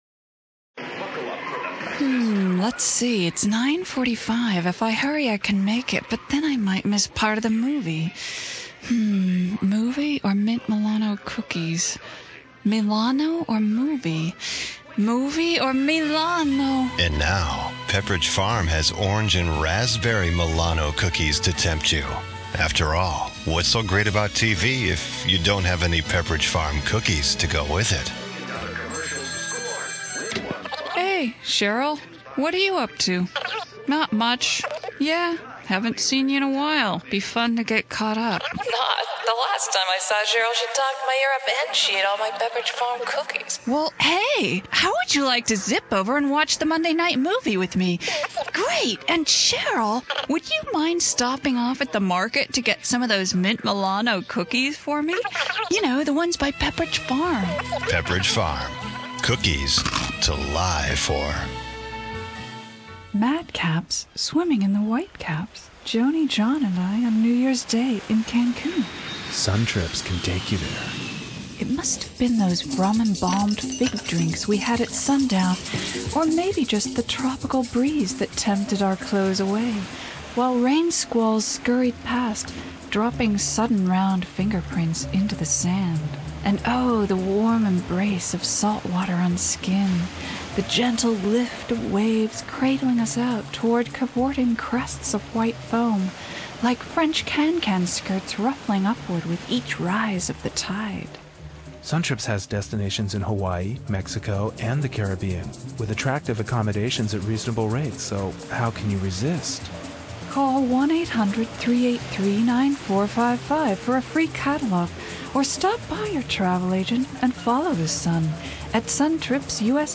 60 second Radio Spots
Demo reel written and produced as an intern at KFOG radio station